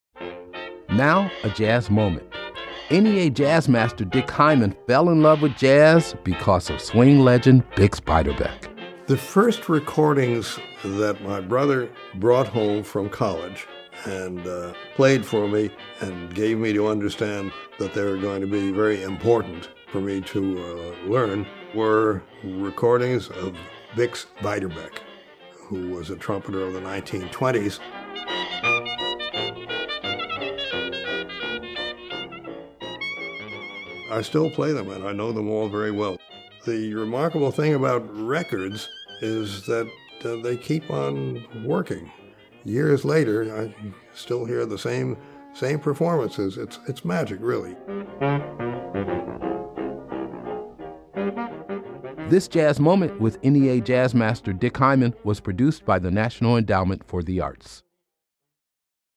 Excerpt of “Royal Garden Blues” by Clarence Williams and Spencer Williams (public domain) and performed by Bix Beiderbecke, from the album Bix Beiderbecke: Vol. II At the Jazz Band Ball, used courtesy of Sony Music Entertainment.